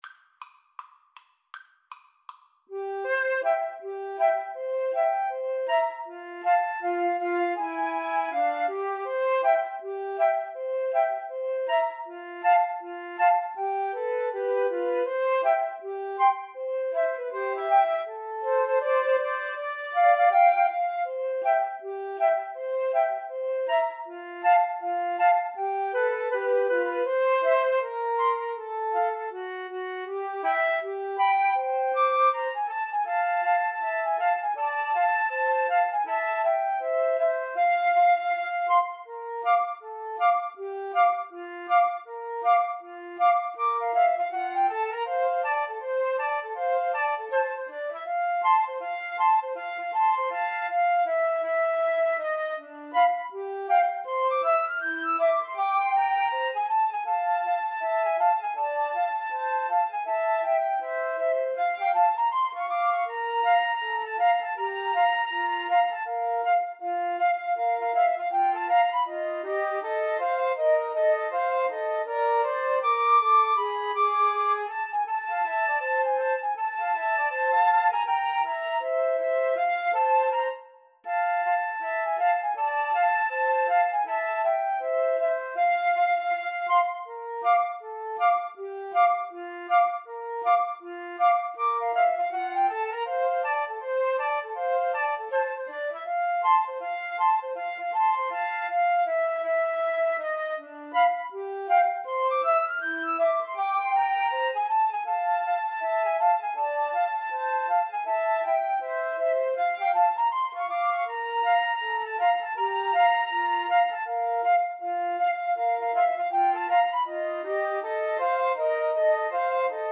4/4 (View more 4/4 Music)
Flute Trio  (View more Intermediate Flute Trio Music)
Jazz (View more Jazz Flute Trio Music)